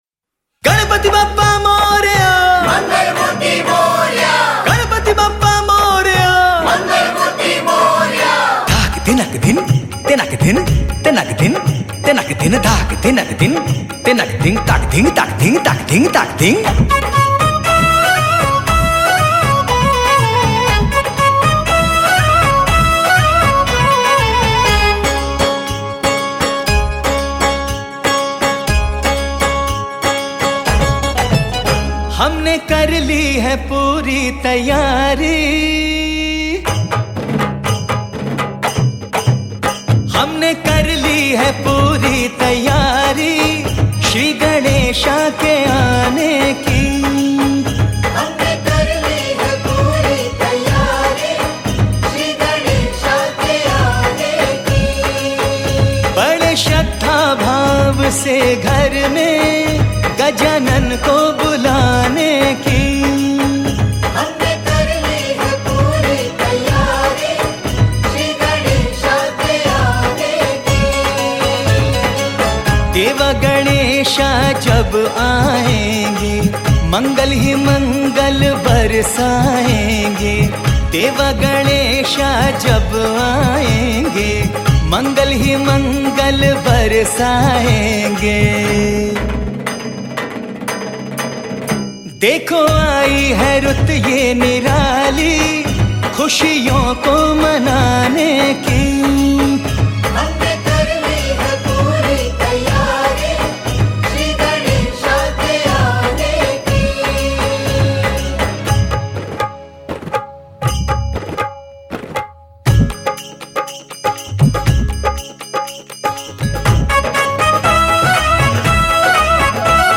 Ganesh Bhajan